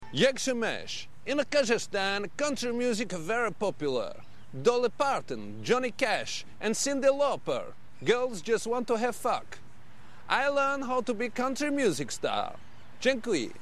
Country Music ringtone download